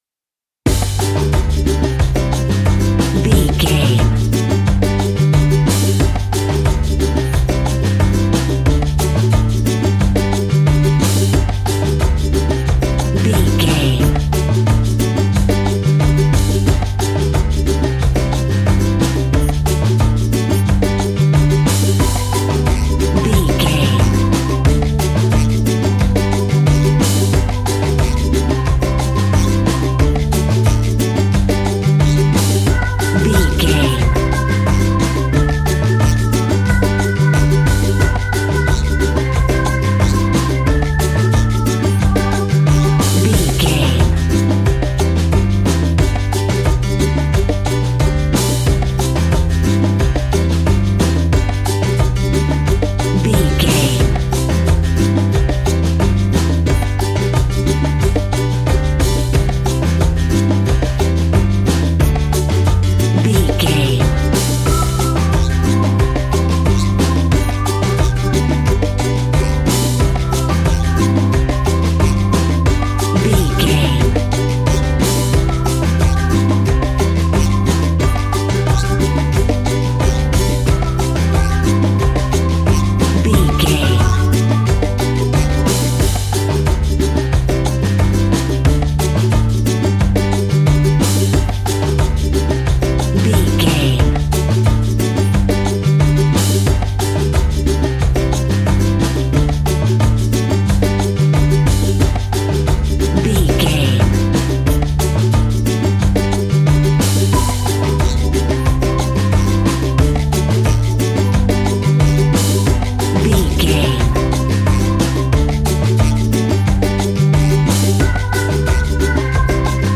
A groovy and upbeat piece of island summer sunshine music.
That perfect carribean calypso sound!
Ionian/Major
steelpan
drums
bass
brass
guitar